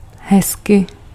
Ääntäminen
IPA: /moːi̯/